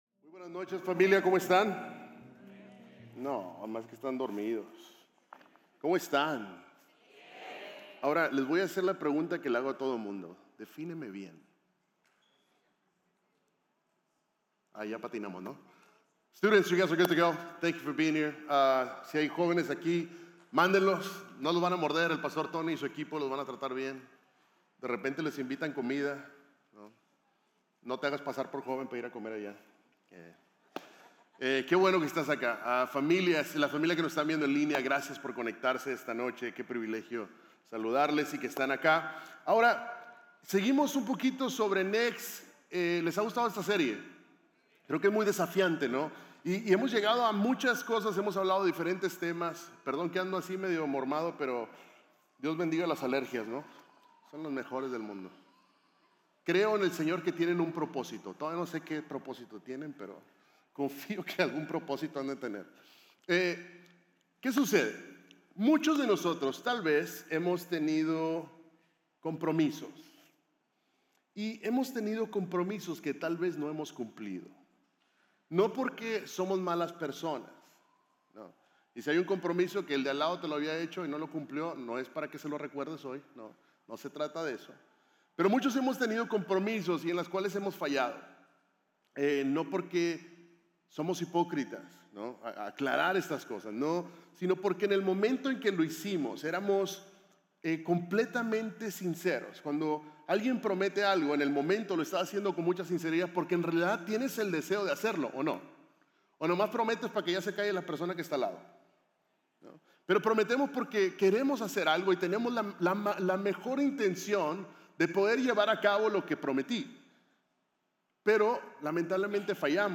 Series de Sermones – Media Player